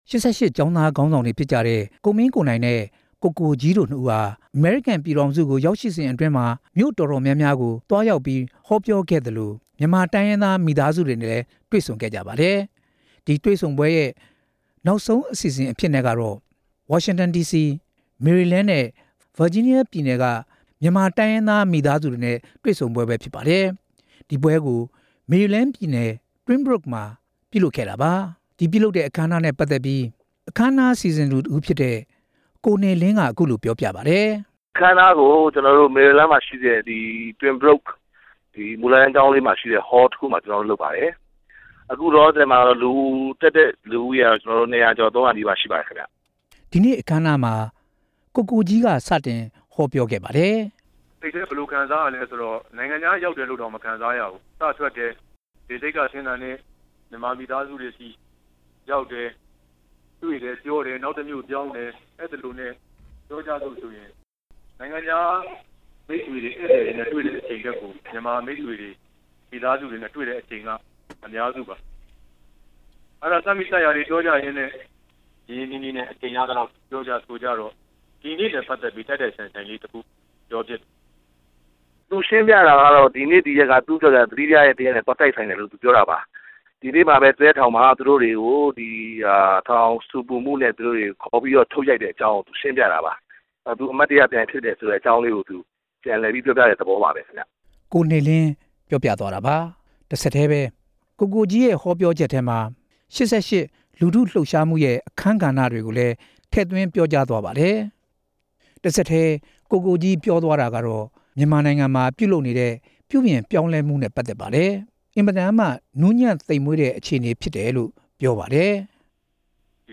အခမ်းအနားနဲ့ ပတ်သက်ပြီး တင်ပြချက်
အခမ်းအနားကို မေရီလင်းပြည်နယ် Twinbrook မူလတန်းကျောင်းမှာ ပြုလုပ်တာဖြစ်ပြီး မြန်မာနိုင်ငံသား ၂၅ဝ ကျော် တက်ရောက်ပါတယ်။